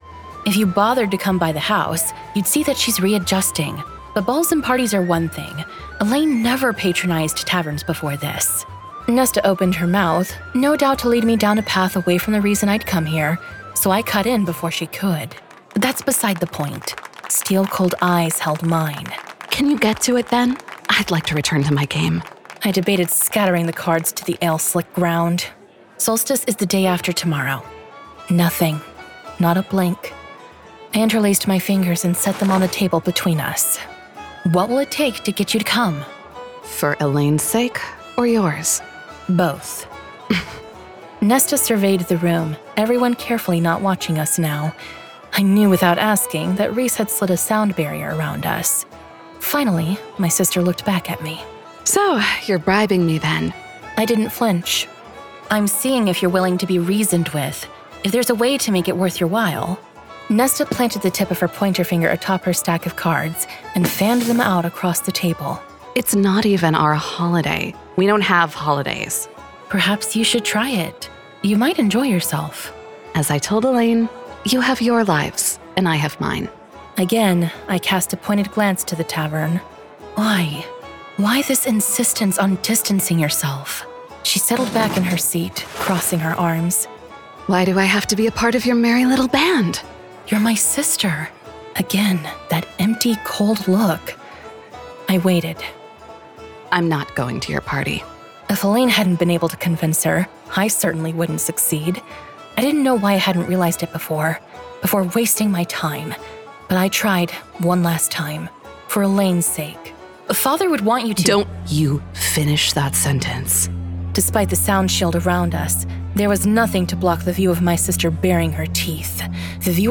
Full Cast. Cinematic Music. Sound Effects.
[Dramatized Adaptation]
Genre: Fantasy Romance